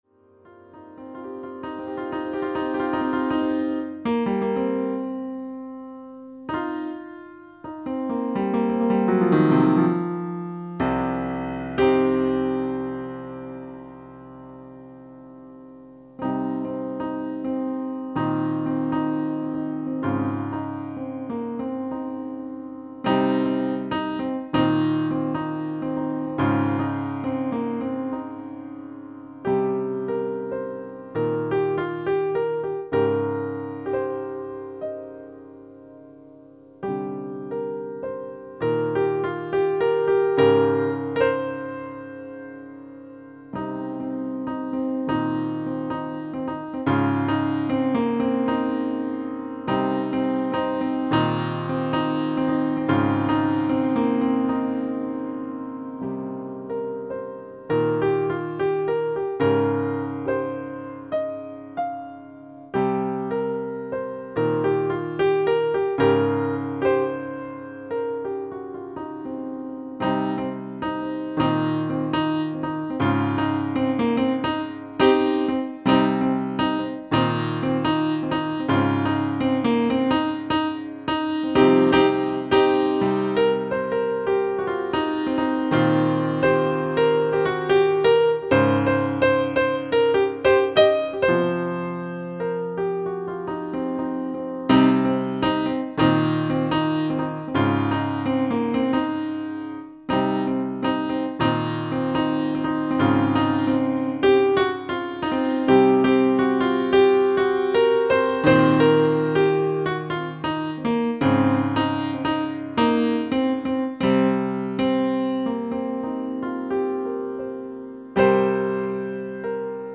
Instrumental piano track.
Main melody returns in variations back.